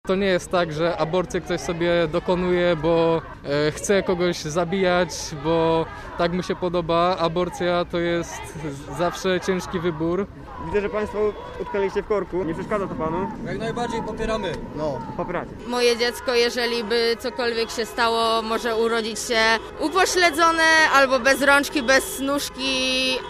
Zebrani przed wejściem na Międzynarodowe Targi Poznańskie przy Moście Dworcowym wznoszą obraźliwe okrzyki pod adresem partii rządzącej.